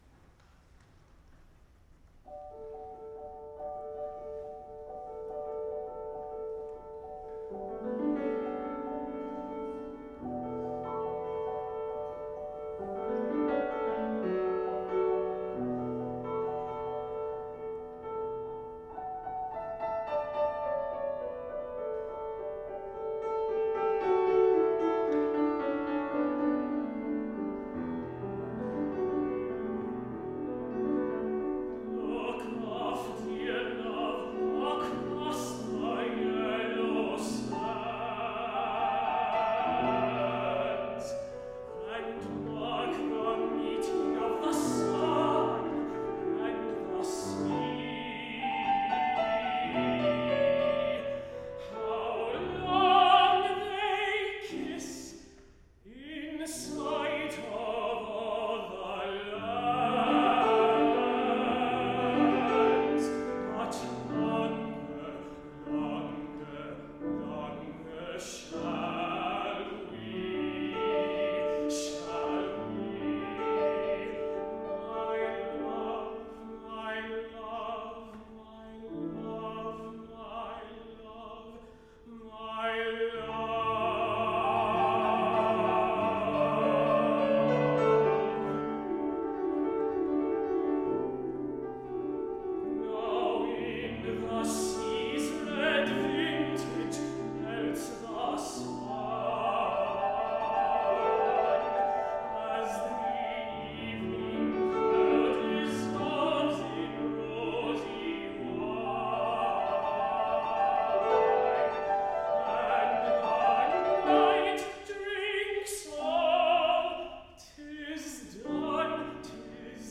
A simple melody is accompanied by steady, walking rhythms.